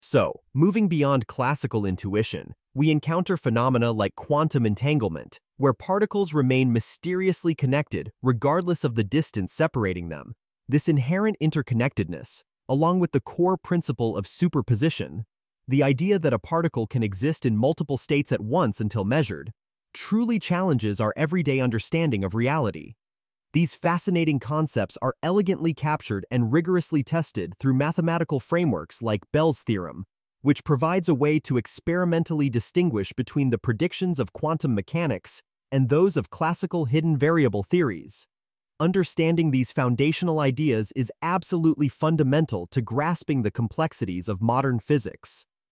lecture_snippet.mp3